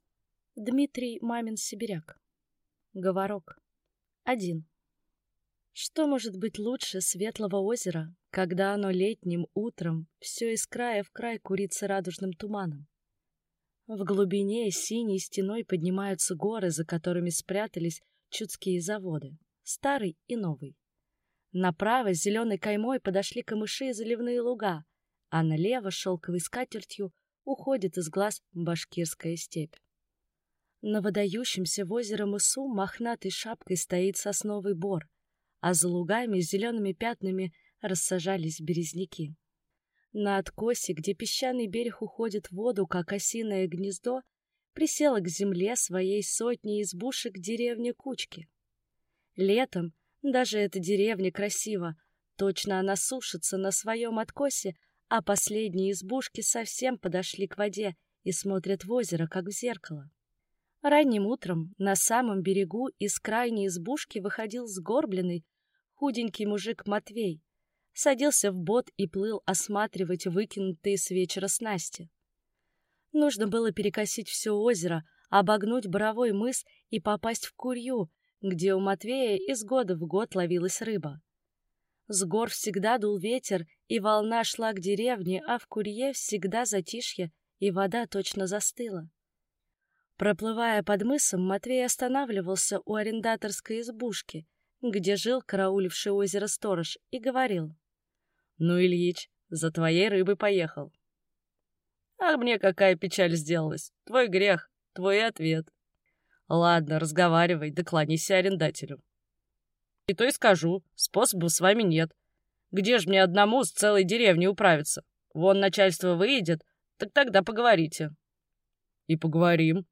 Аудиокнига Говорок | Библиотека аудиокниг